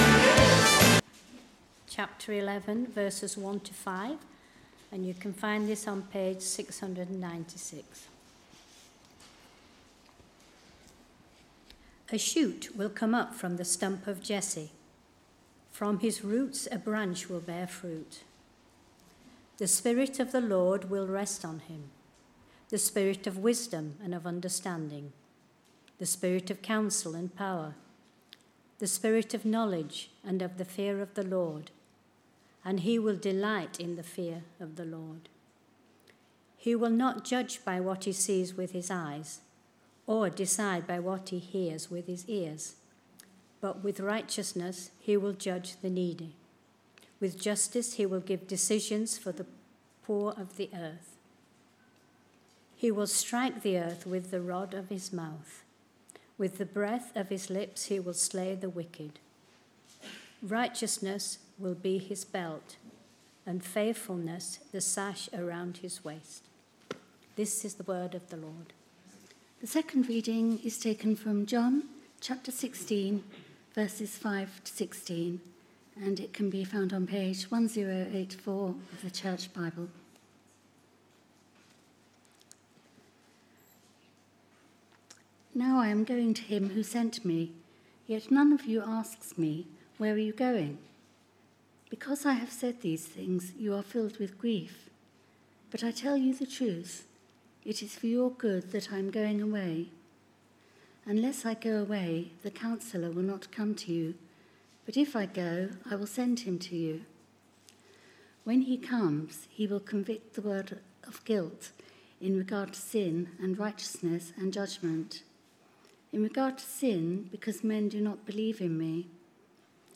Readings-sermon-on-8th-March-2026.mp3